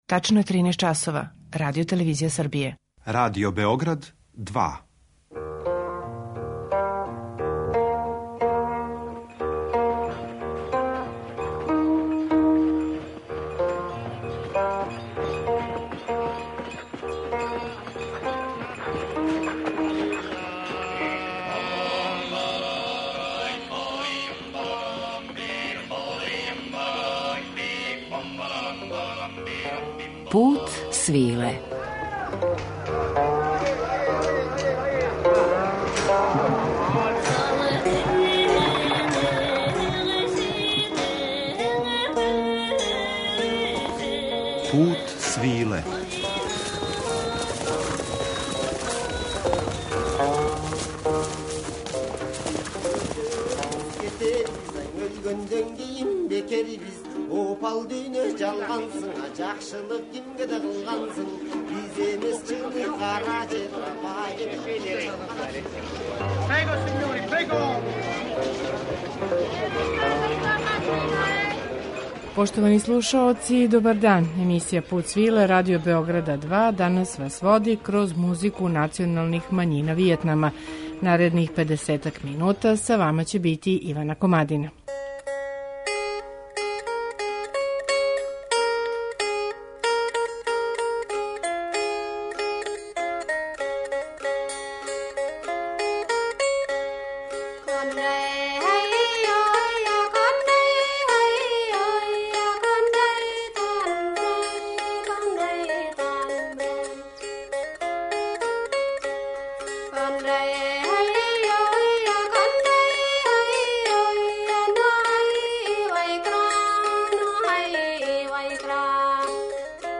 Музика националних мањина Вијетнама
У сложеној церемонији ове свечаности учествују и шесторица мушкараца који свирају дугачке уздужне флауте, које производе само по један једини тон. Мелодија се остварује пажљивим међусобним усклађивањем.
Њихови дланови су при том повијени, па стварају снажну струју ваyдуха која изазива треперење ваздушног стуба у бамбусовој свирали.